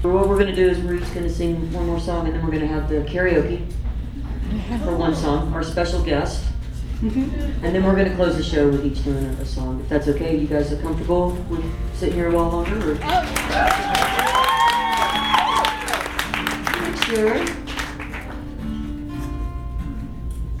lifeblood: bootlegs: 2003-08-24: red light cafe - atlanta, georgia (atlanta harm reduction center benefit) (amy ray)